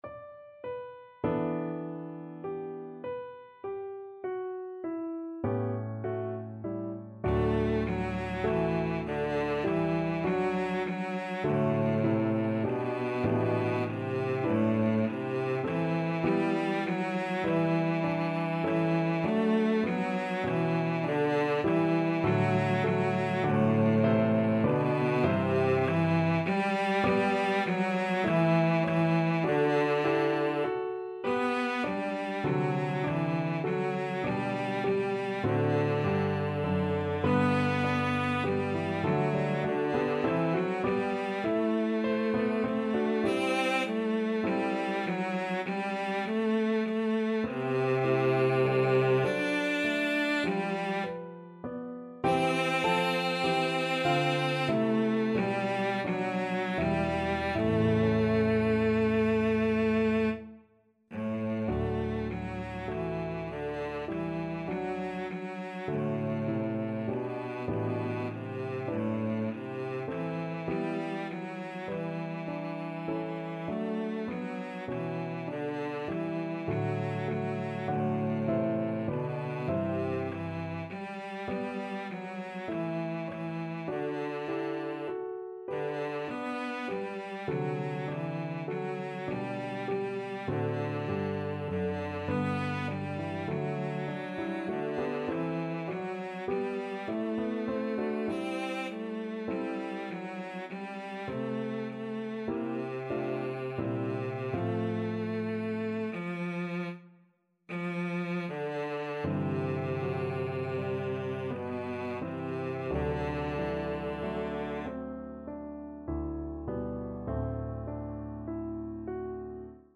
5/4 (View more 5/4 Music)
A3-D5
Classical (View more Classical Cello Music)